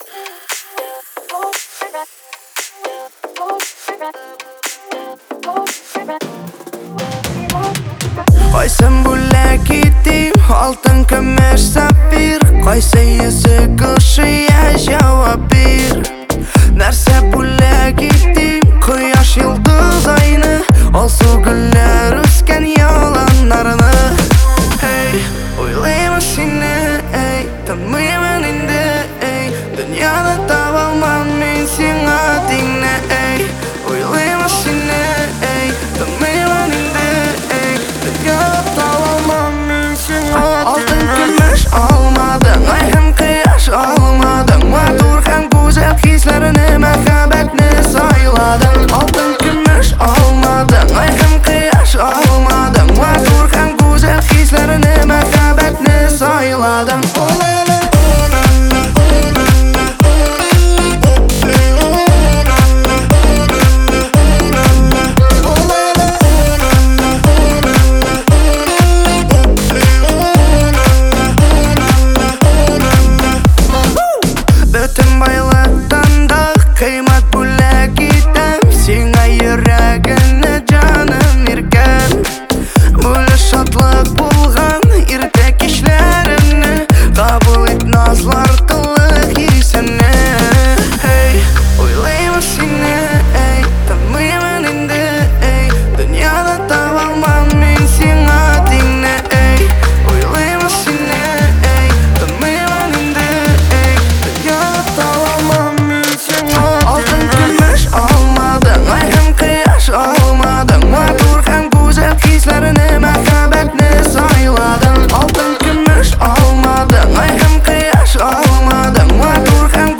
звучит уверенно и мелодично, создавая атмосферу праздника